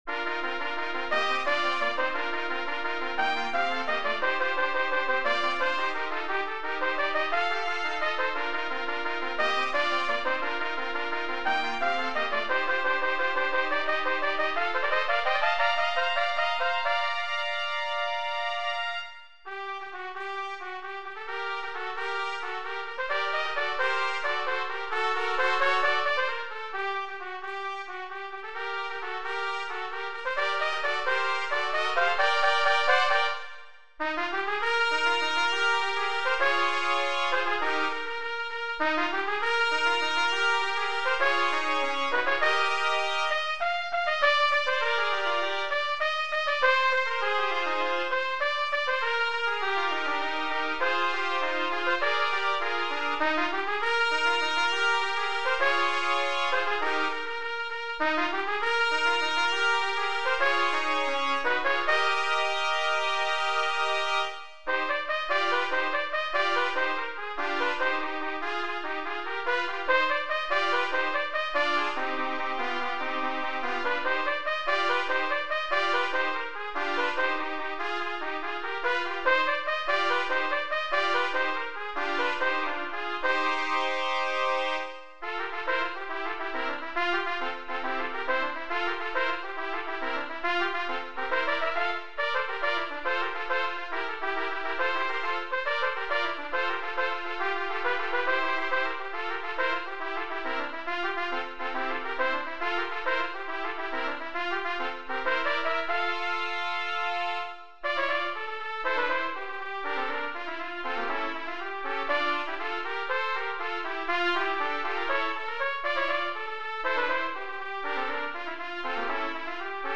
Voicing: Trumpet Trio